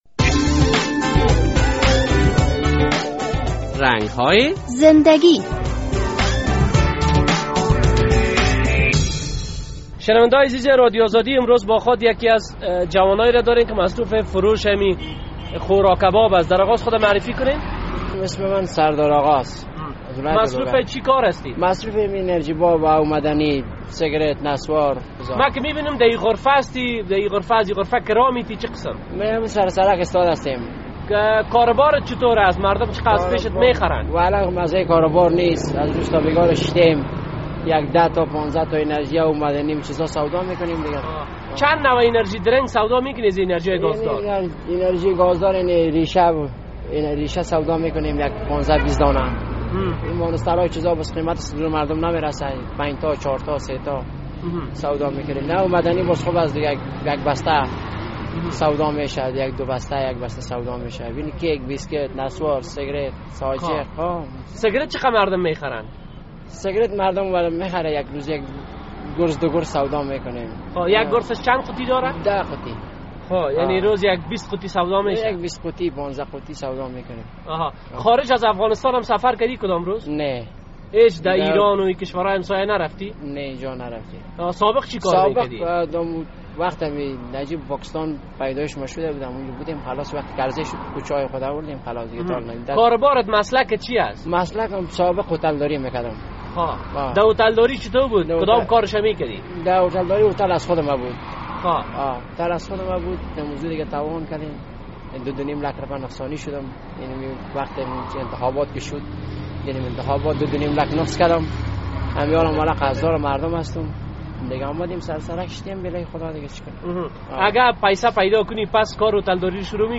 در این برنامهء رنگ‌های زنده‌گی خبرنگار رادیو آزادی با یک دوکاندار مصاحبه کرده است...